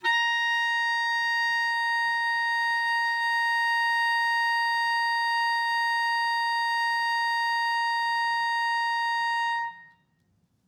DCClar_susLong_A#4_v3_rr1_sum.wav